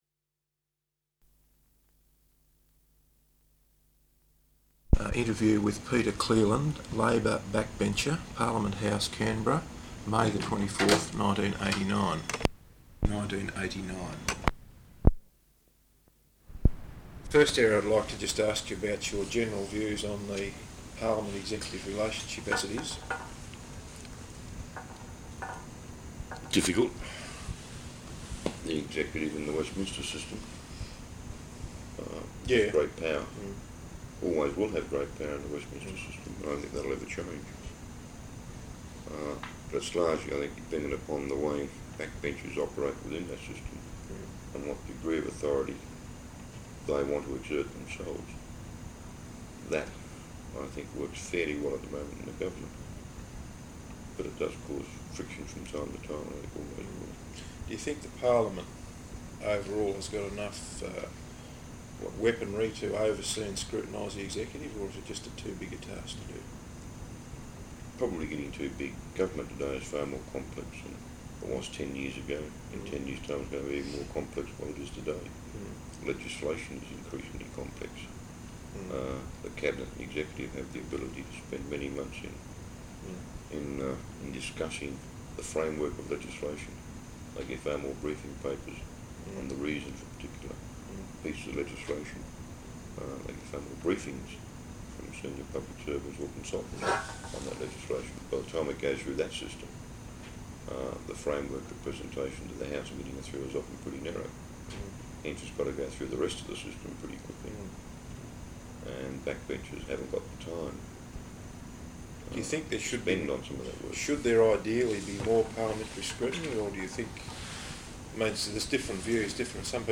Interview with Peter Cleeland, Labor Backbencher, Parliament House, Canberra May 24th 1989.